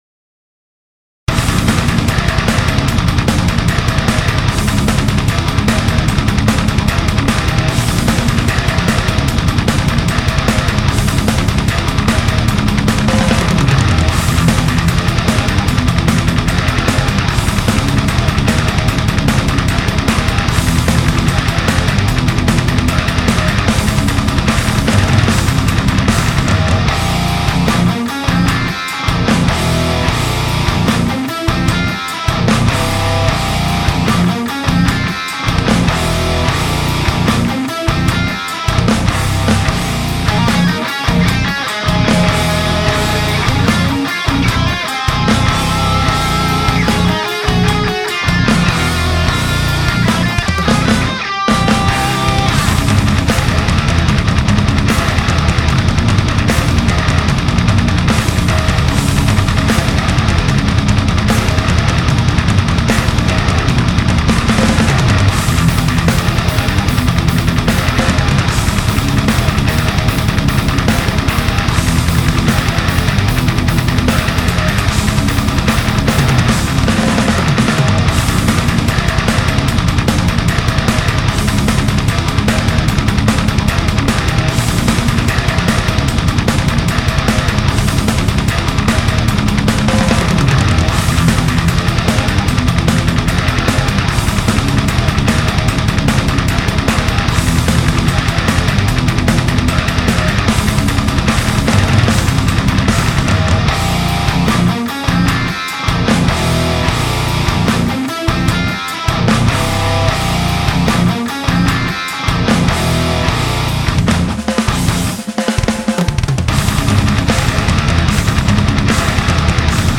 latest \m/etal wack-off session
Pissed around in Renoise/Reaper for a few hours this morning.
Pretty much a complete mix/master sans vocals because I hate my voice as well as writing lyrics though this was written with vocals in mind so prepare for some repetition.